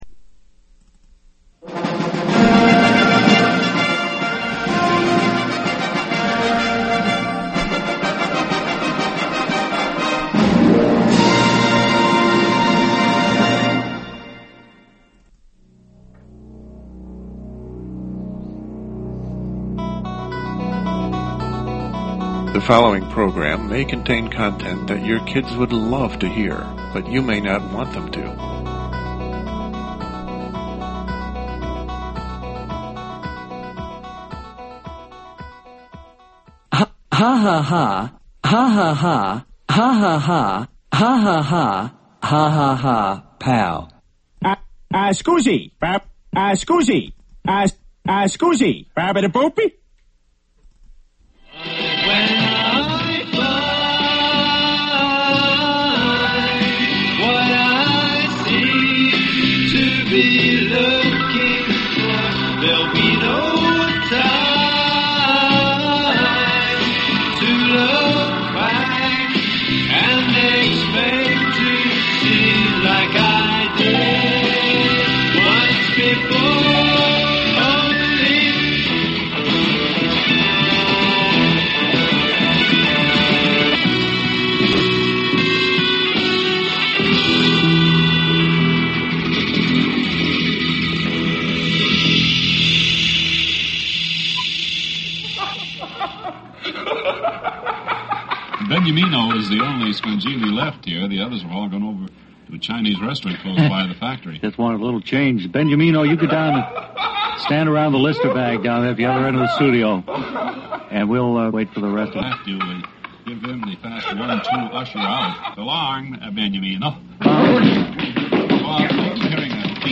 The first hour of the Feb. 28 show will be a satirical tribute to the origins of progressive-rock radio. In 1967 FM stations began to drop classical-music formats, replacing them with a hip, “meaningful music” soundscape where the DJ donned a near-guru personality to engine the train of musical thought.